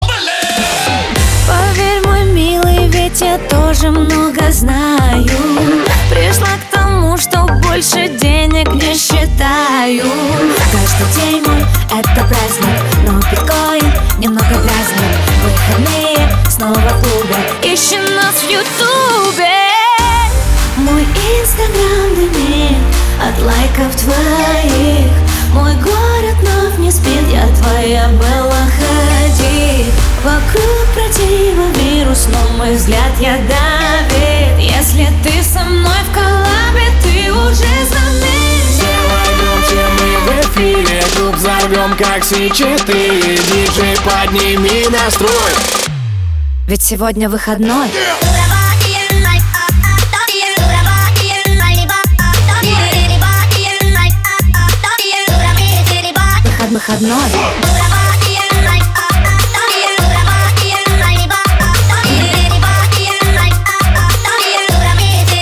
• Качество: 320, Stereo
поп
позитивные
зажигательные
веселые